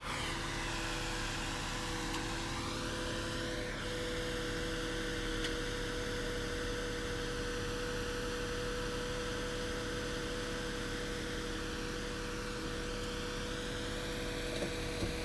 Jeep engine 01.wav